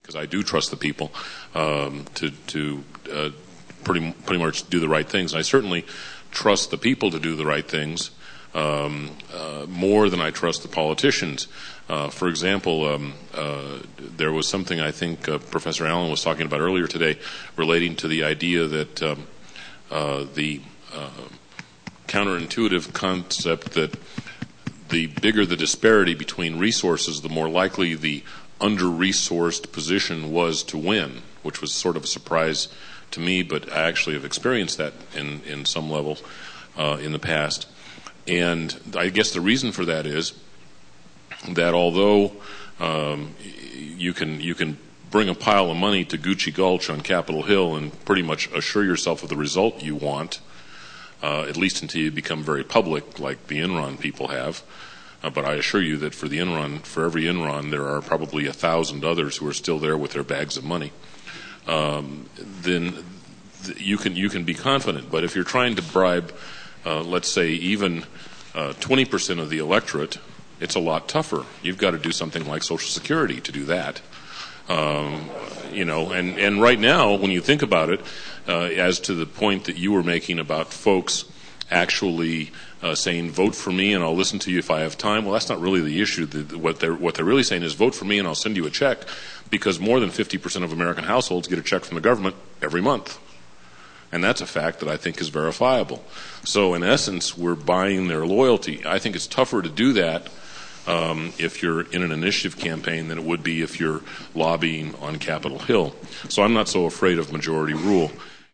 Unidentified Male